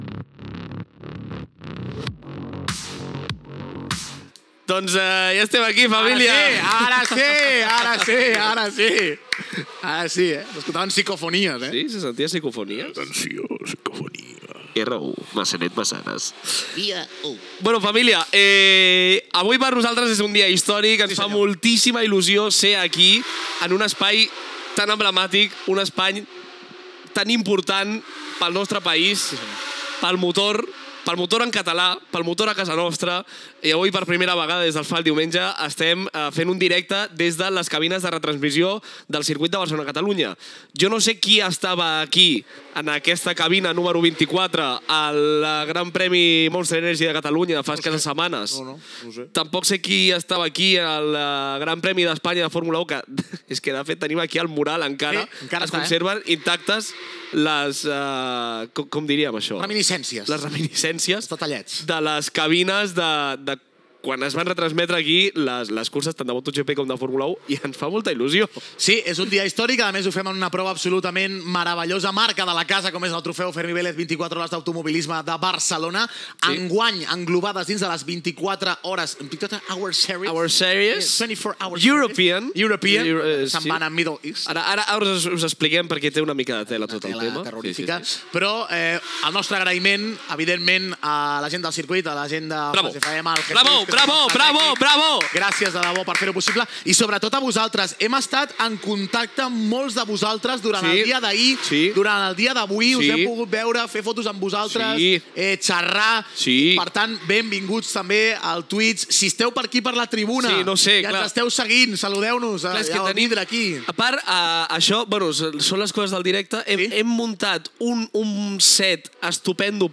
Transmissió a Twitch de l'última hora de les 24 hores d'Automobilisme de Barcelona - Trofeu Fermí Vélez, des del Circuit de Catalunya Gènere radiofònic Esportiu